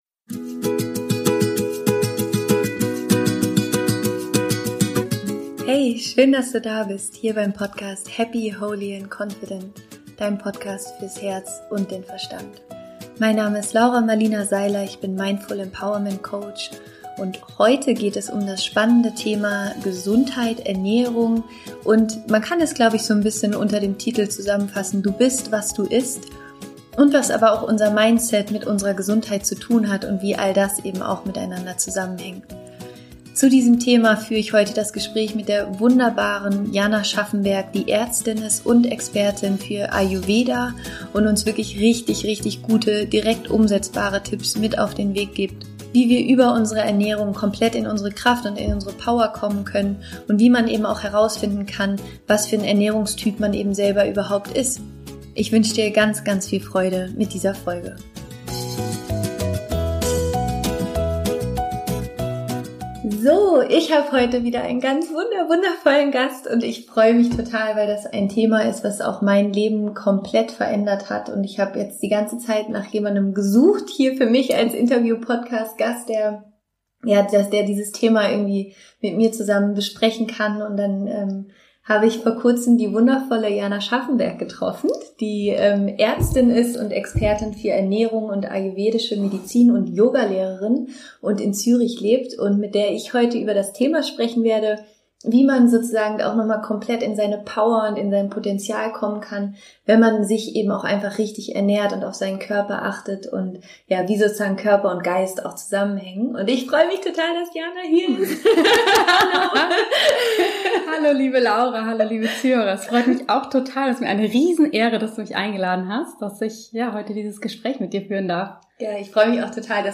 Du bist, was du isst - Interview